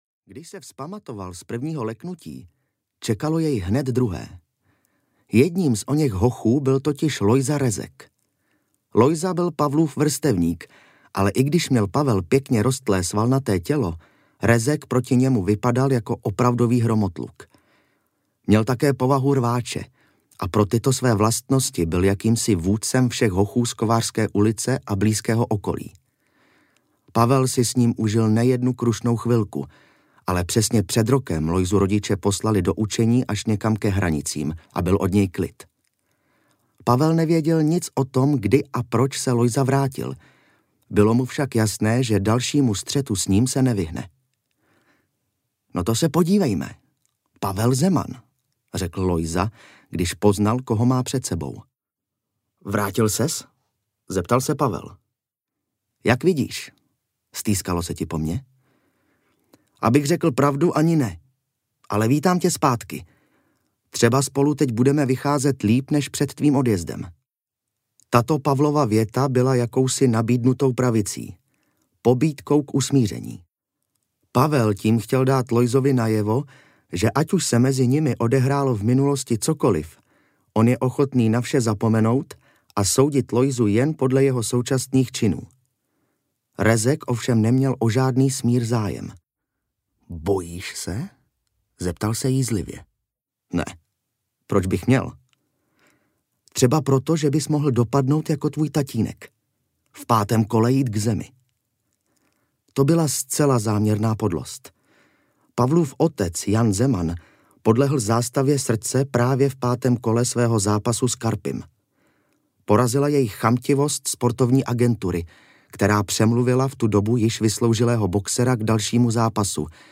Zkáza Jezerní kotliny audiokniha
Ukázka z knihy